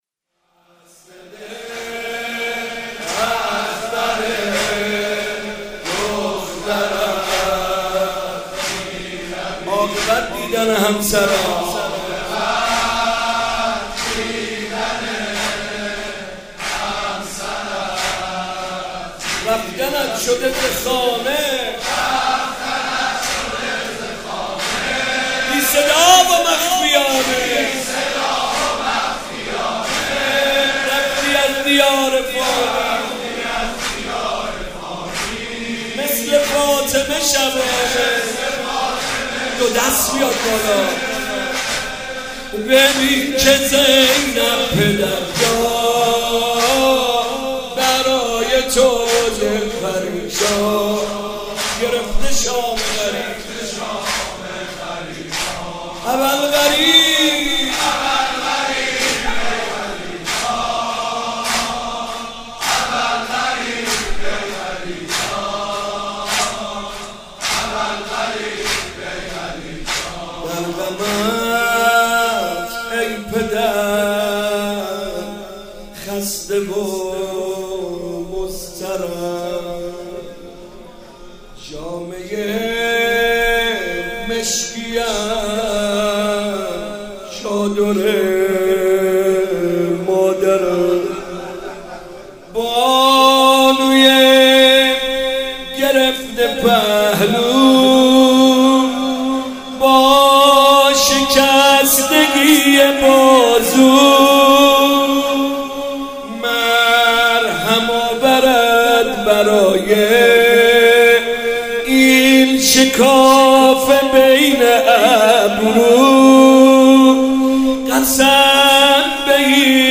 ماه رمضان 96
ماه رمضان محمد رضا طاهری نوحه